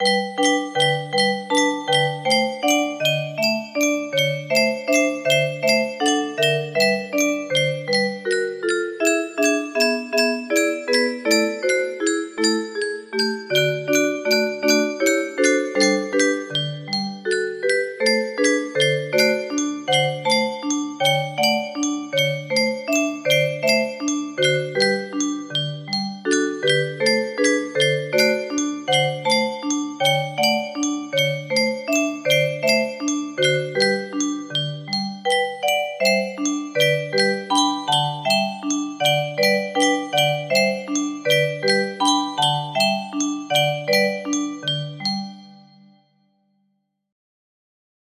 Anónimo - Gusto music box melody
Aire nacional mexicano de Michoacán